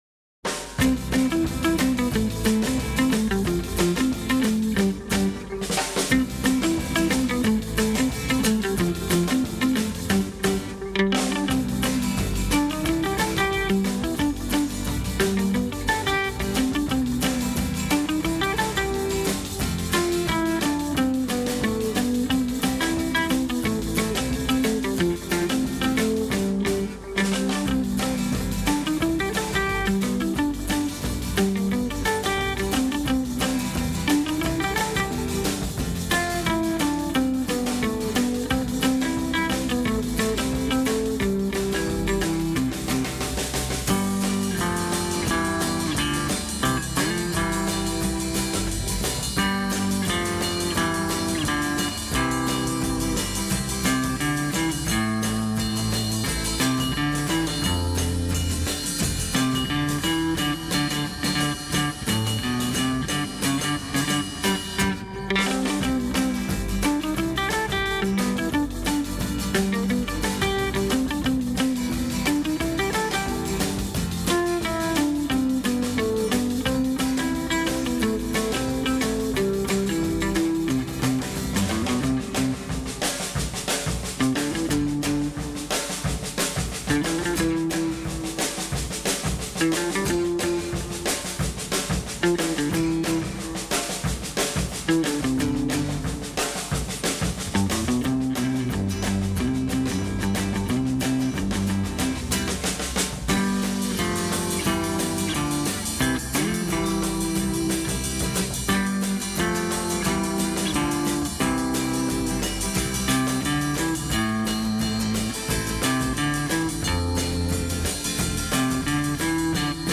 lead guitar
rhythm guitar
bass
drums
B* " : 4 drum/guitar idea repeated for ending